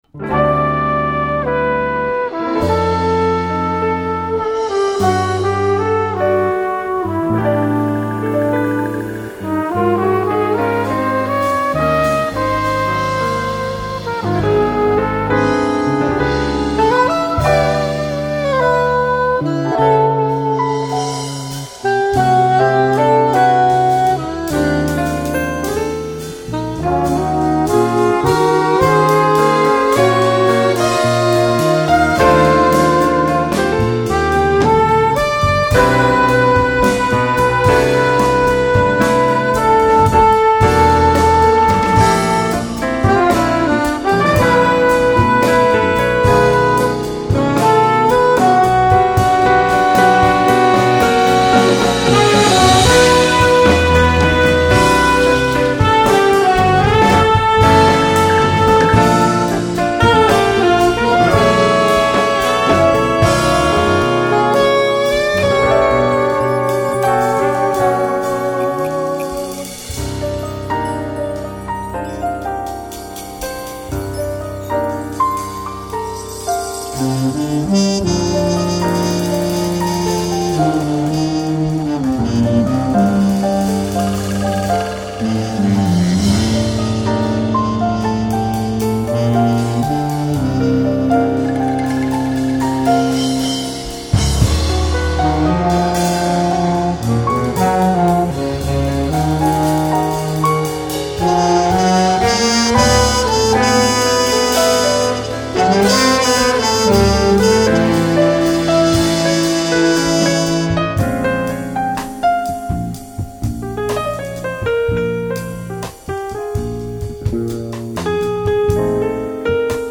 ECM style ballad